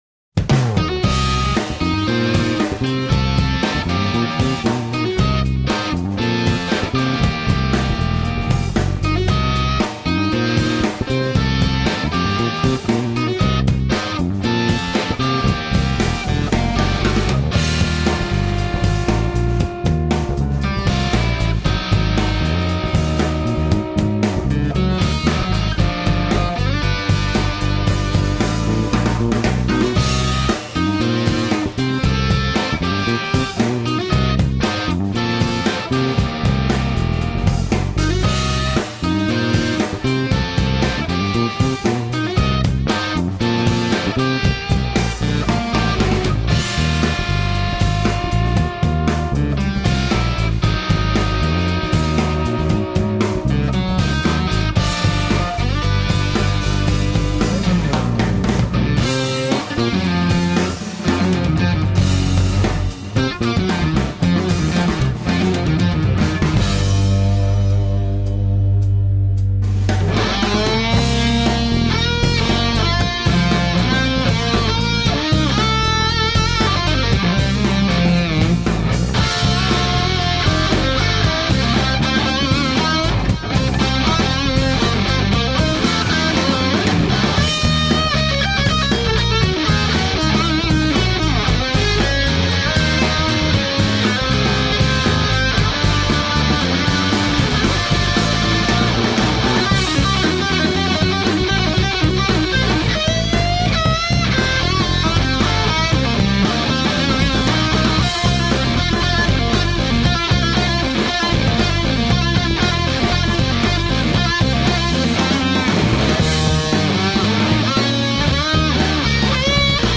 Raunchy blues rock.
Tagged as: Alt Rock, Blues